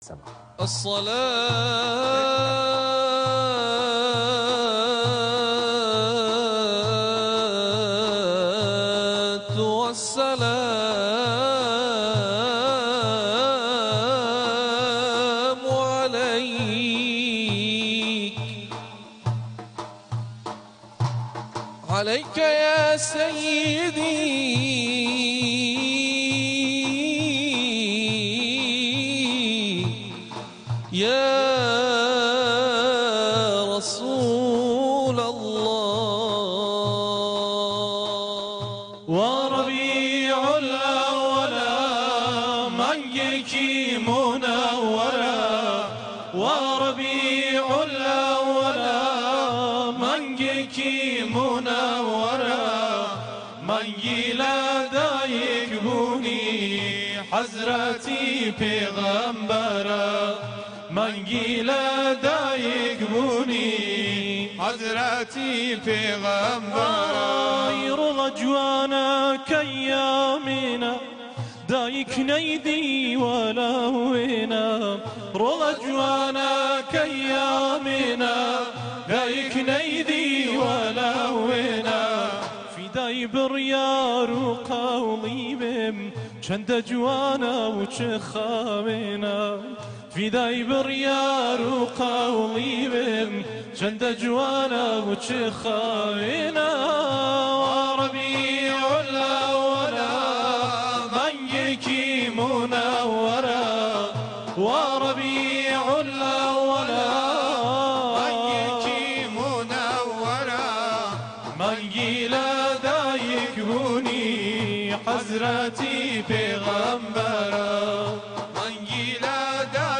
مولودی‌خوانی شنیدنی به زبان کُردی
مولودخوانی